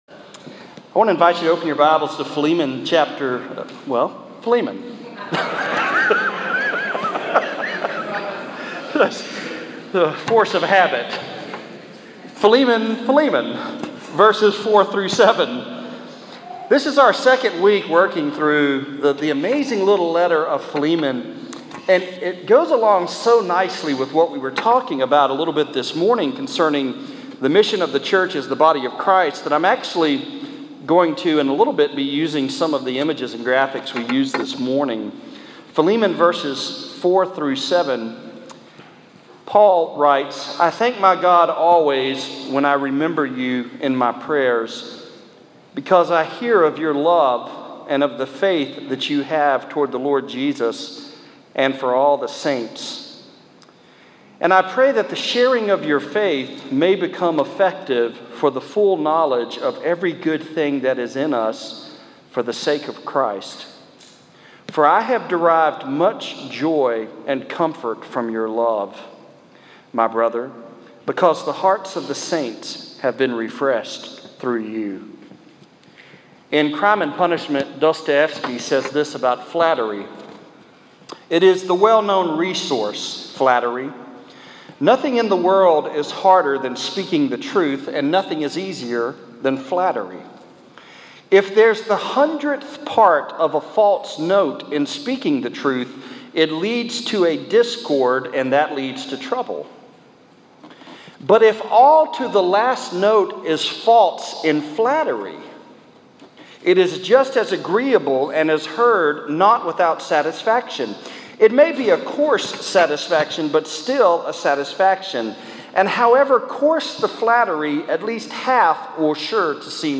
Philemon 4-7 (Preached on July 3, 2016, at Central Baptist Church, North Little Rock, AR)